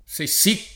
se no [Se nn0+] avv. — fam. sennò [id.]: sennò ci rimetto il posto [Senn0 ©©i rim%tto il p1Sto] (Berto) — solo gf. divisa se correlativo a un se sì [